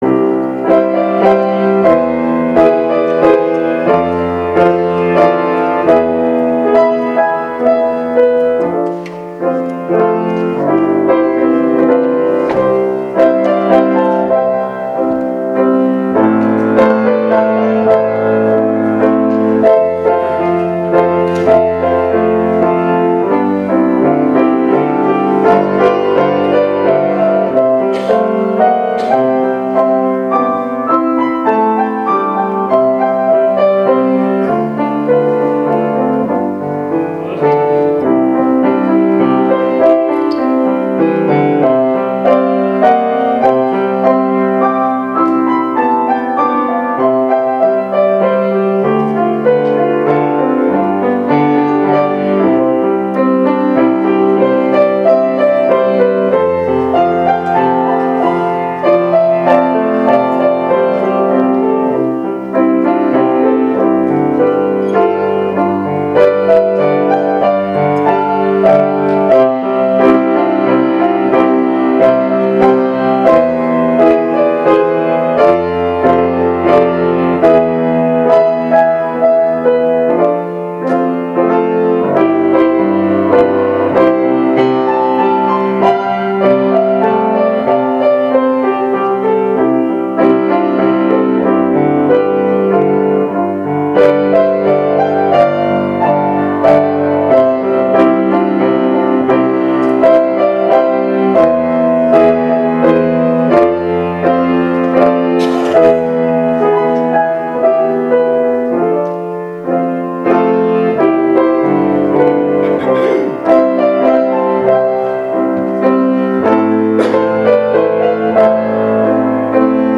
Walker Church SERMONS
Piano Solo